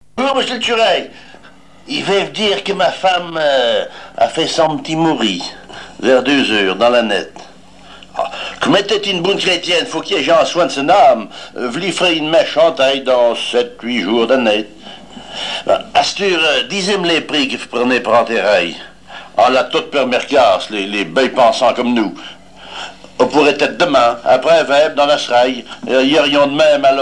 Patois local
Genre récit